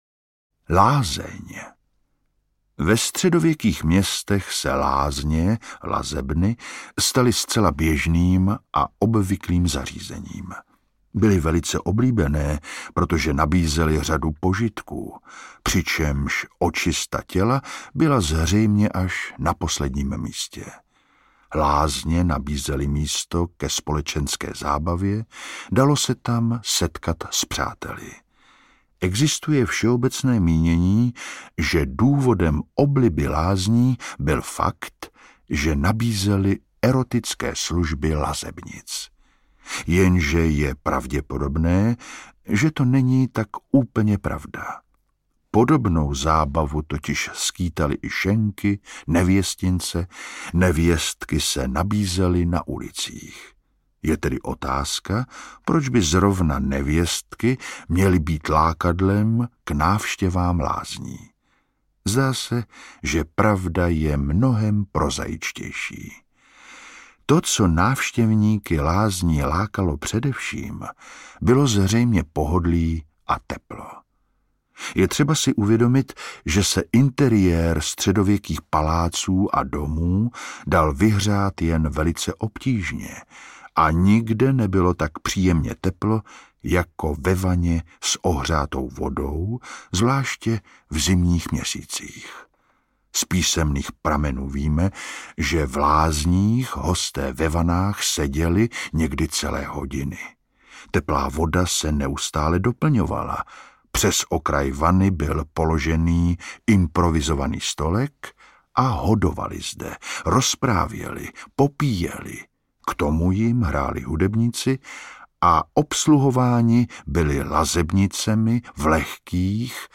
Ukázka z knihy
zivot-ve-staletich-14-stoleti-audiokniha